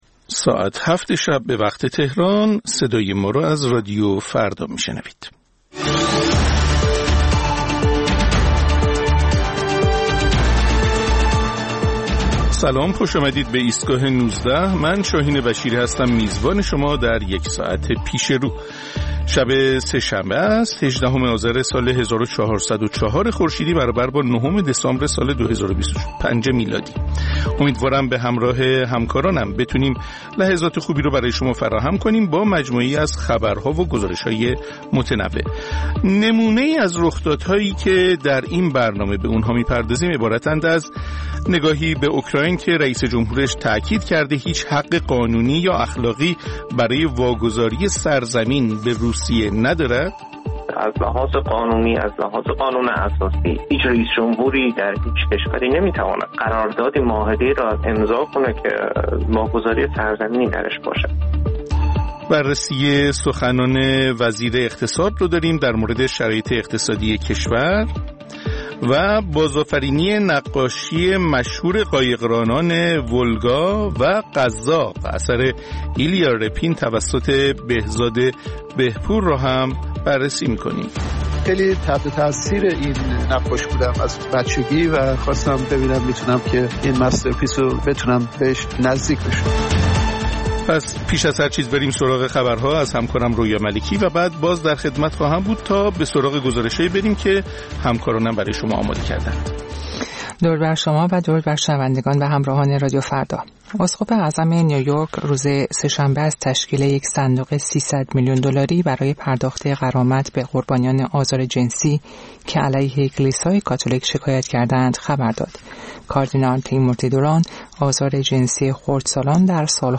مجموعه‌ای از اخبار، گزارش‌ها و گفت‌وگوها در ایستگاه ۱۹ رادیو فردا